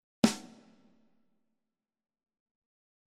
AlestormSnare.mp3